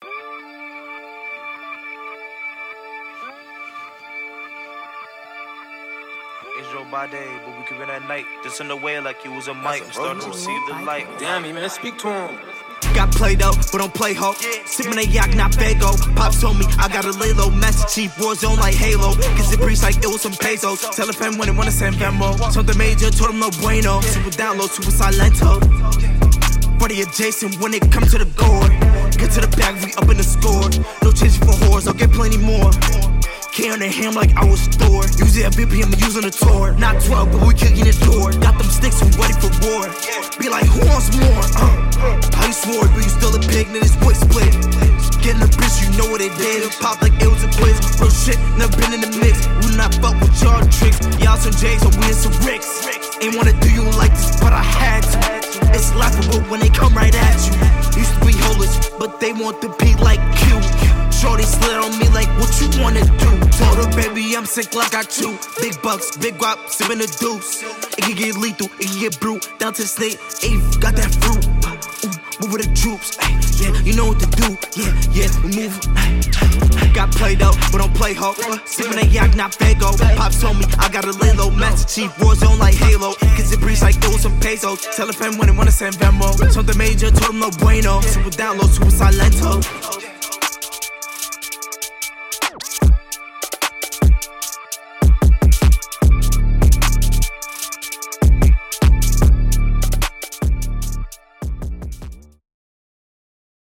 RECORDED, MIXED, MASTERED